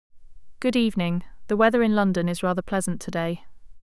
02_design_female_british — voice design, female british
영어(00_auto_en)는 꽤 자연스러운데, 한국어(01_auto_ko)는 톤이 많이 평평하고 억양이 어색한 느낌이 있어요. voice design 쪽은 female_british, male_low, whisper 모두 지정한 캐릭터가 어느 정도 구분돼 들리긴 해요.
AI, TTS
02_design_female_british.wav